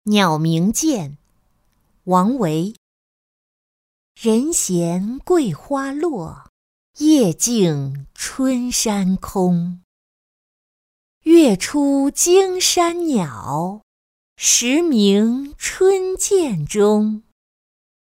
丽人行-音频朗读